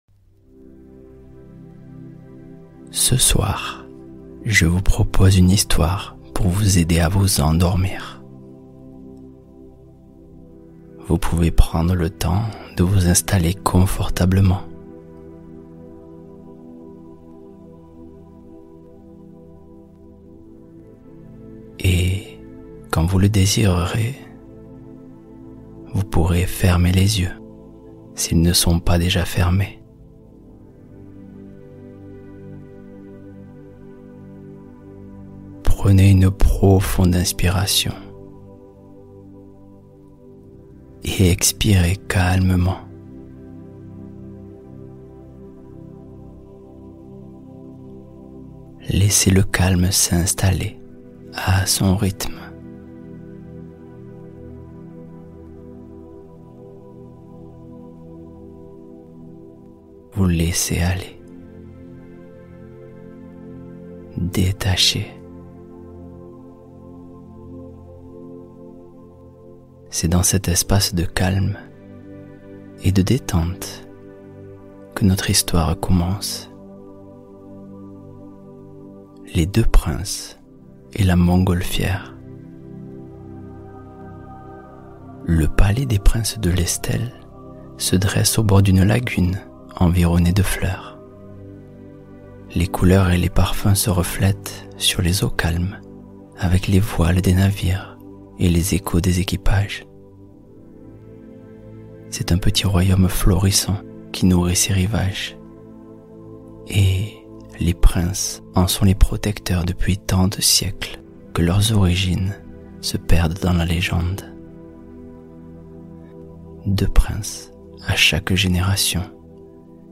Les Deux Princes : Histoire onirique pour un endormissement en douceur